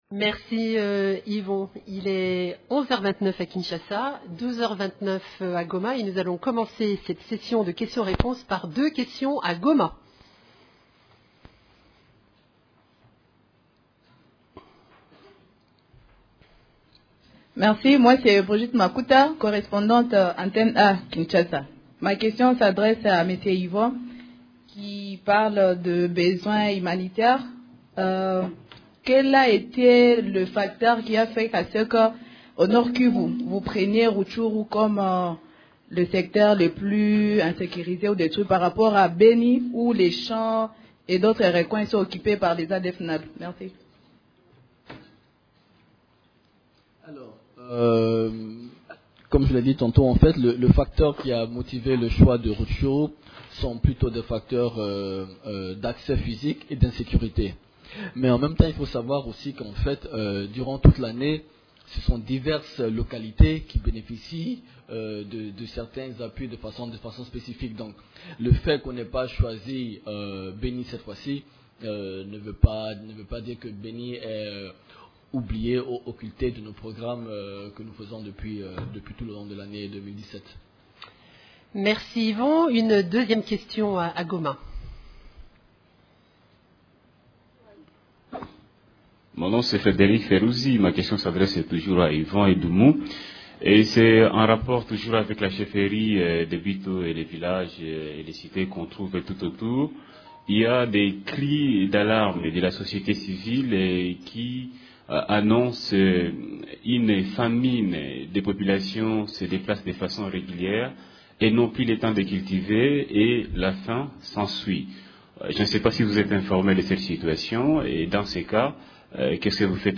Conférence de presse du mercredi 15 novembre 2017
La situation sur les activités des composantes de la MONUSCO, les activités de l’Equipe-pays ainsi que de la situation militaire à travers la RDC ont été au centre de la conférence de presse hebdomadaire des Nations unies du mercredi 15 novembre 2017 à Kinshasa: